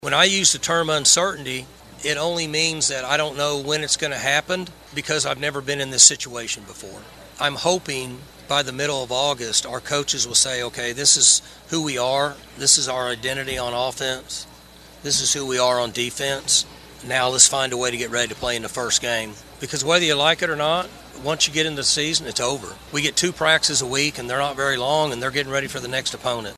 Oklahoma State head football coach Mike Gundy took the podium for Big 12 Media Days yesterday in Frisco, Texas.